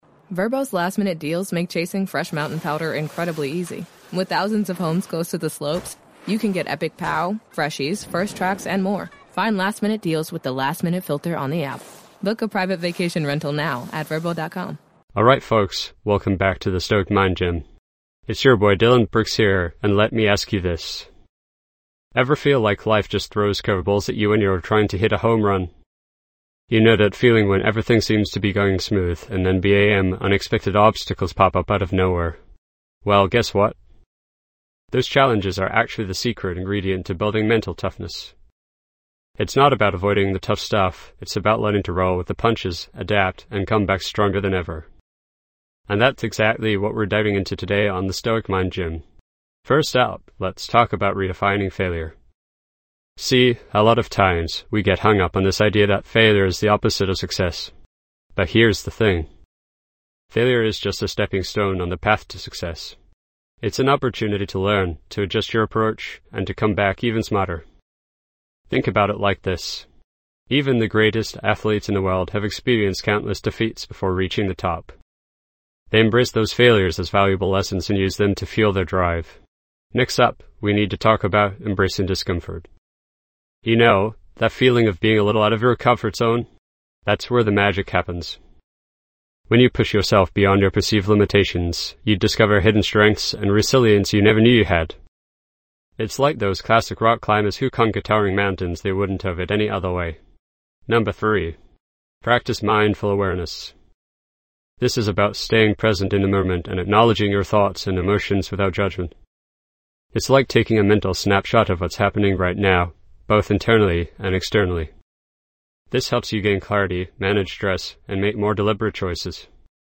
Self-help, Personal Development, Mental Health, Inspirational Talks
This podcast is created with the help of advanced AI to deliver thoughtful affirmations and positive messages just for you.